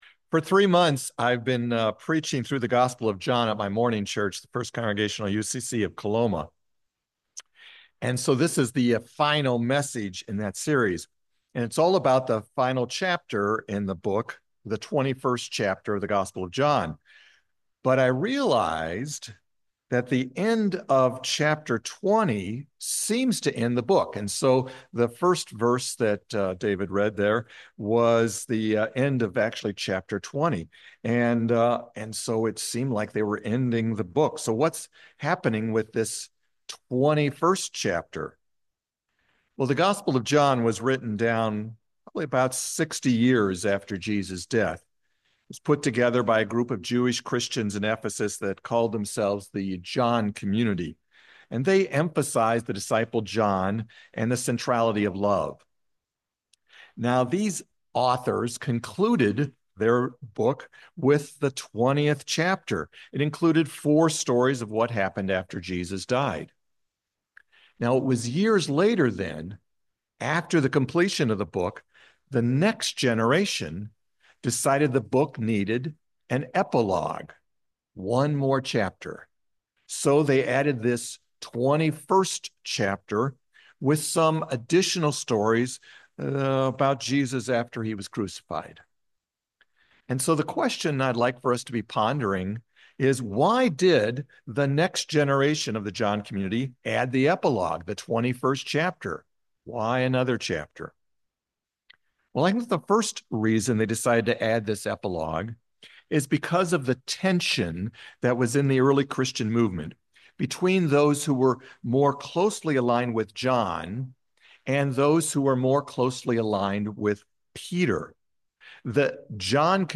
A message from guest speaker